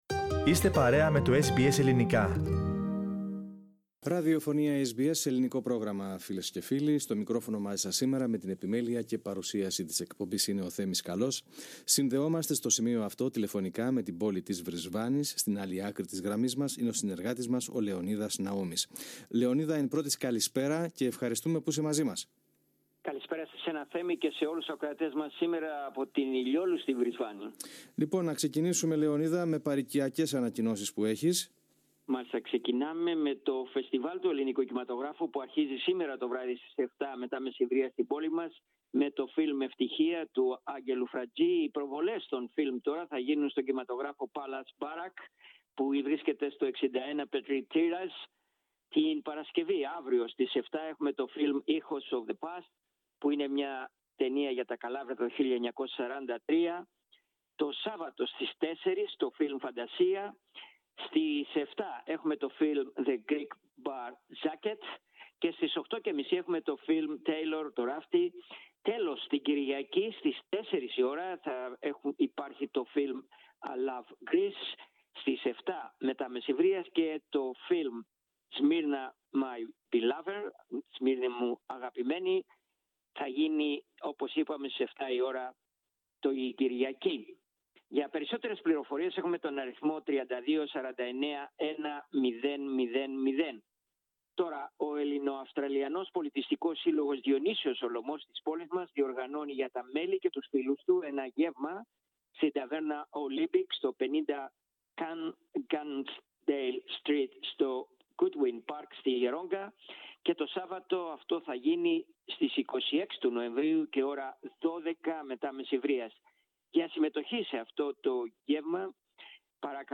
Ακούστε την εβδομαδιαία ανταπόκριση από την Βρισβάνη της Κουηνσλάνδης.